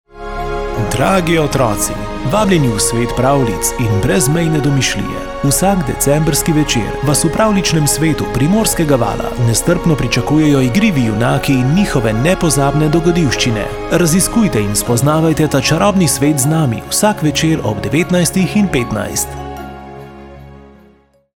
PRAVLJICE-2020-JINGLE.wav